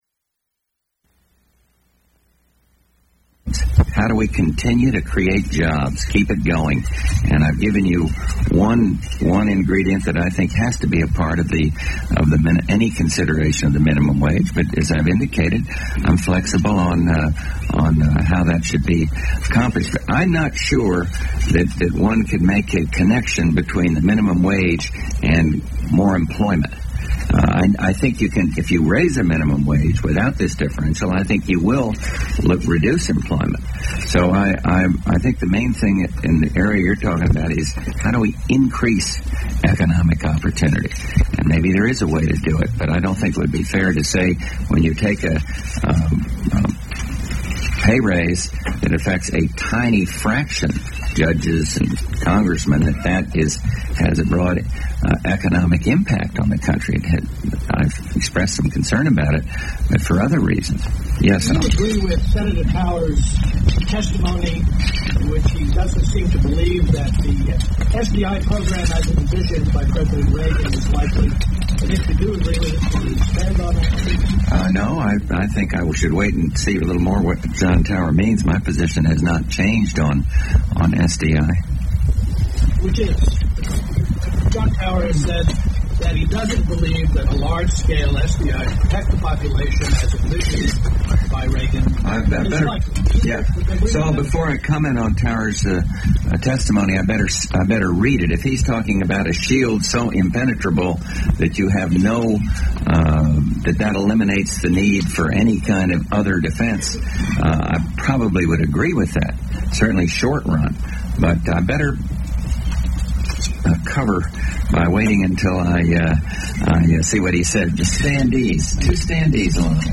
First presidential press conference of President George Bush
Broadcast on CNN, January 27, 1989.